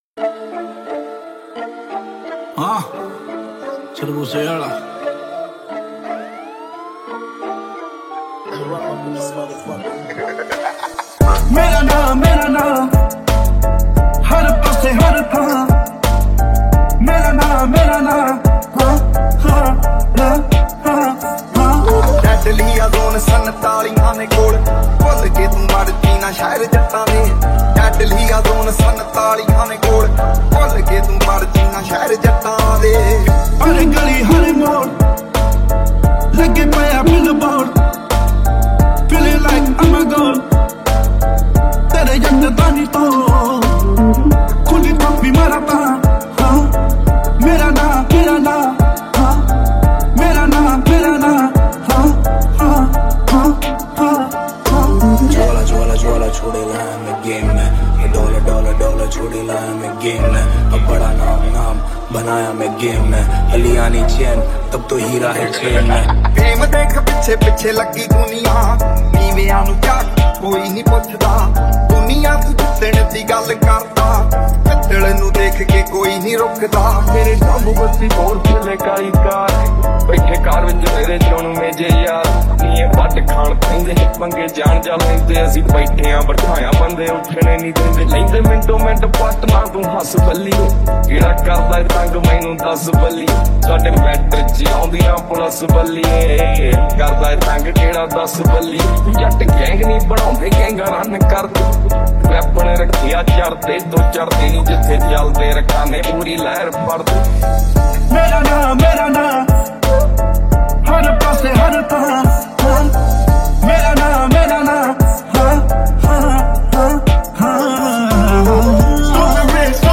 Category New Dj Song 2023 Singer(s